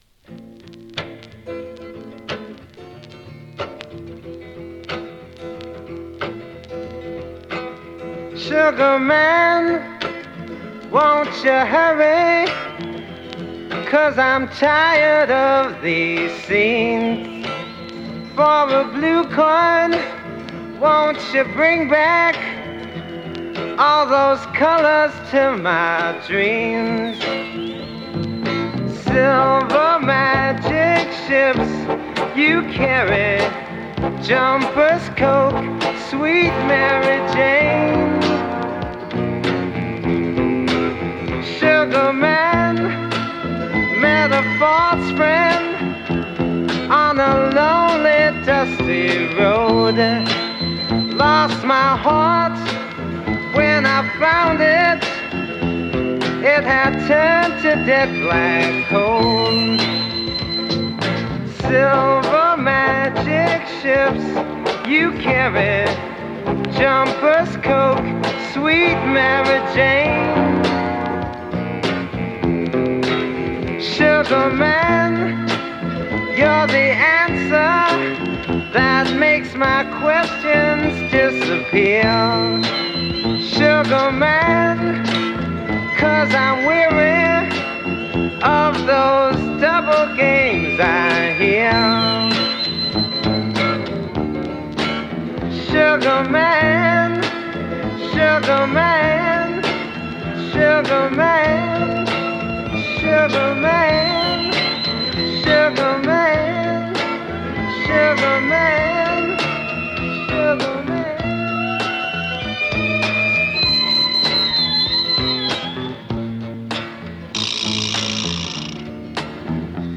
アシッドフォークやブルース、カントリーのエッセンスを溶け合わせた傑作です！
盤面にキズ多/全体的にチリパチ・ノイズ。